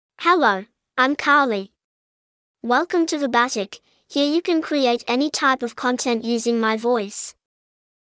FemaleEnglish (Australia)
Carly is a female AI voice for English (Australia).
Voice sample
Female
Carly delivers clear pronunciation with authentic Australia English intonation, making your content sound professionally produced.